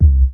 F#_07_Sub_02_SP.wav